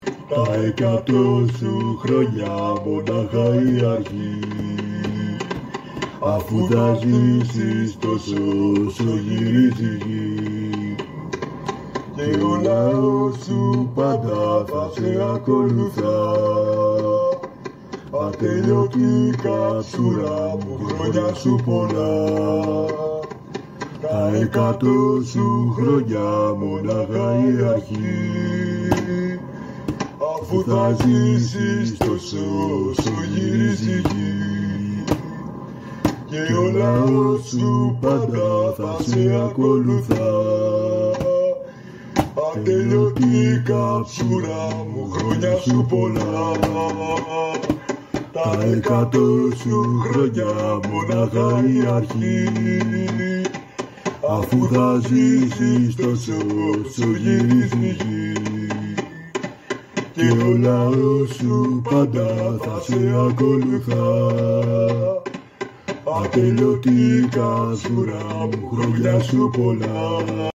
Το επετειακό σύνθημα που θα δονεί την “Αγιά Σοφιά” κόντρα στον Ολυμπιακό (audio)